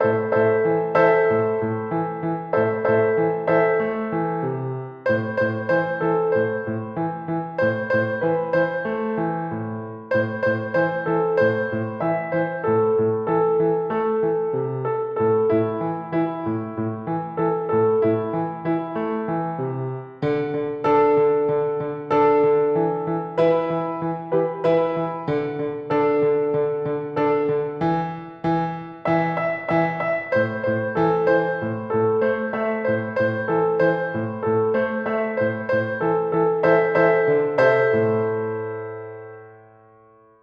Exercise 4: Simple Keyboard Exercise
Piano sight read exercise
Piano sight reading
Piano-Sight-Read-1.mp3